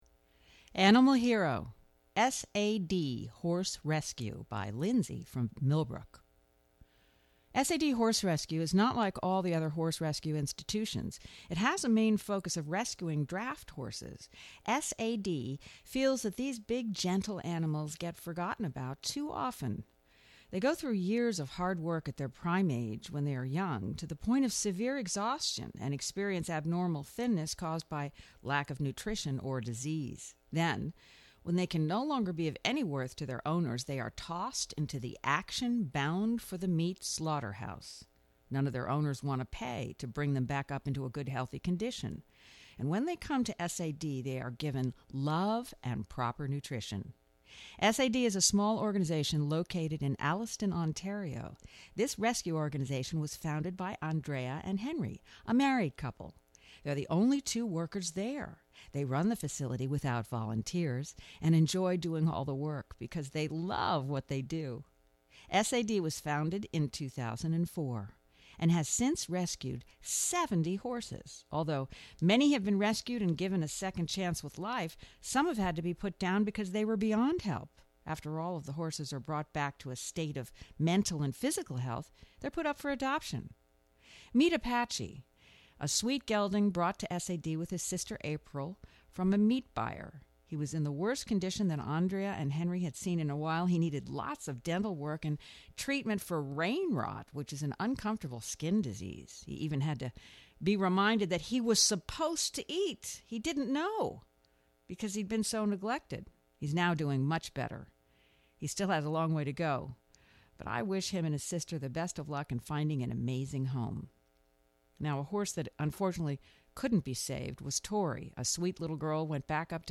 Please enjoy this reading of our MY HERO story S.A.D. Horse Rescue